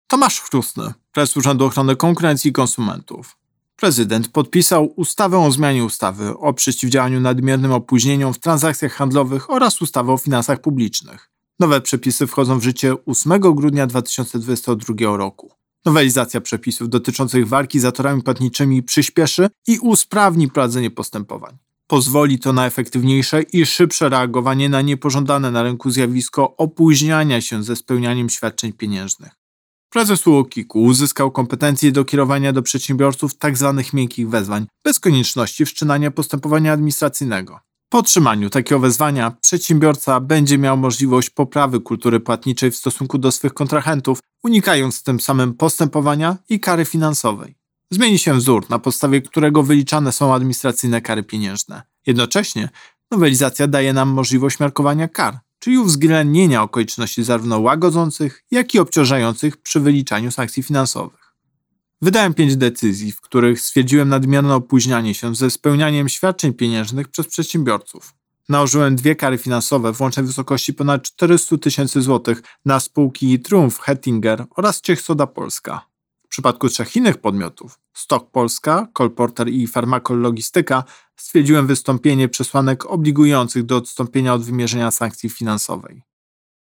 Wypowiedź Prezesa UOKiK Tomasza Chróstnego z 8 grudnia 2022 r..mp3